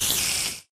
spider1.ogg